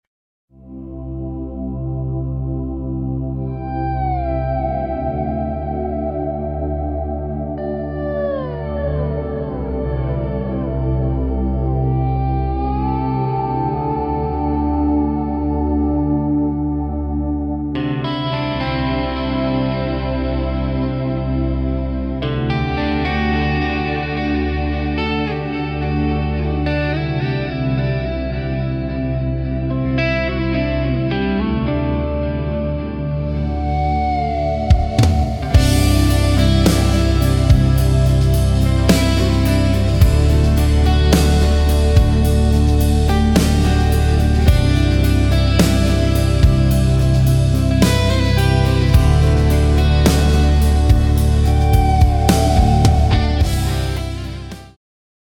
This track represents the C Lydian Mode.